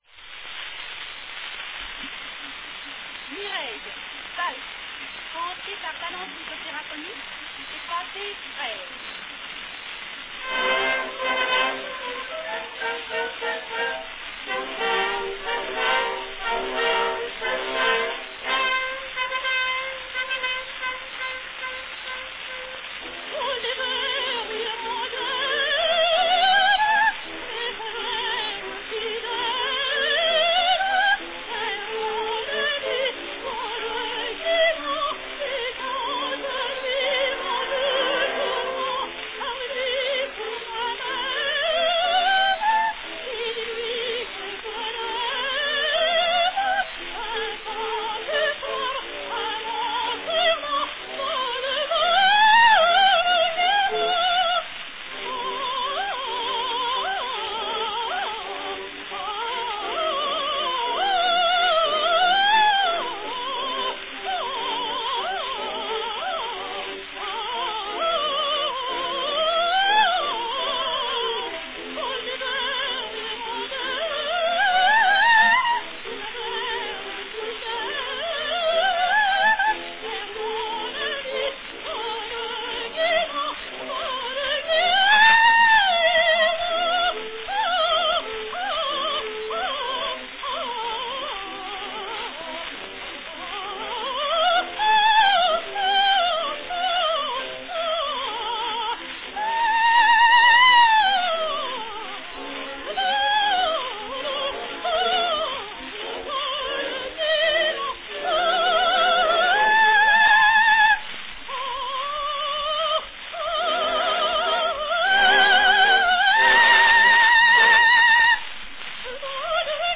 Cylinder # 688 (3½" diameter)
Category Soprano